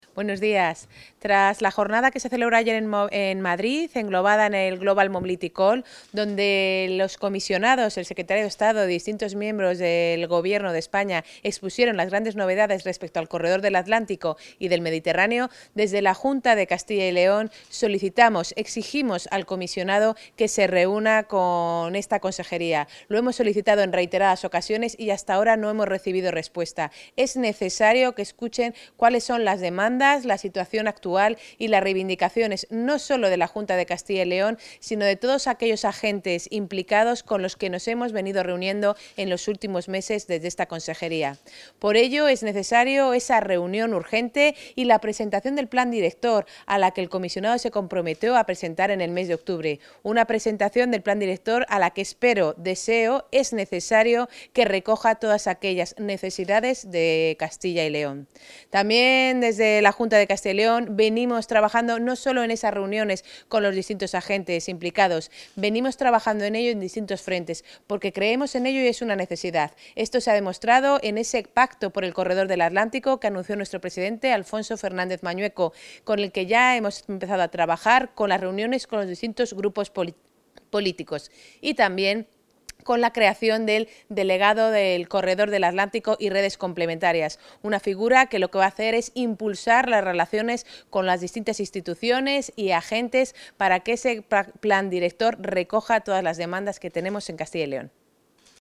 Valoración de la consejera.